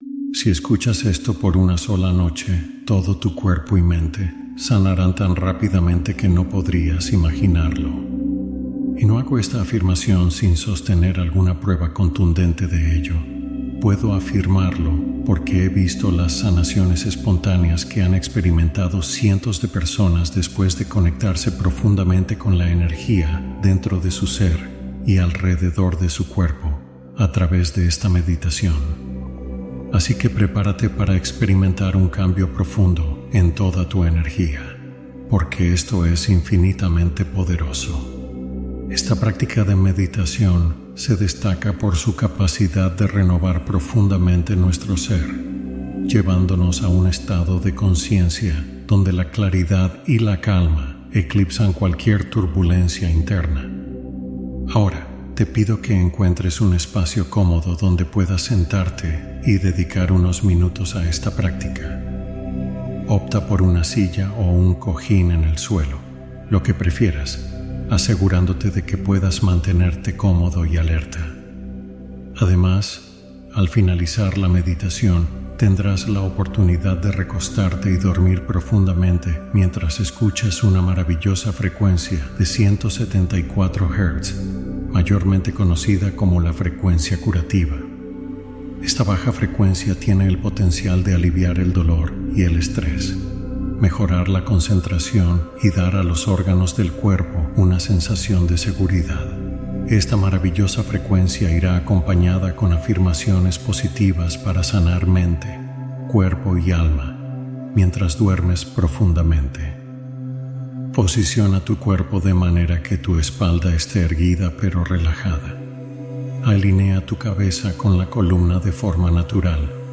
Sanación cuántica profunda: hipnosis para transformar tu subconsciente al dormir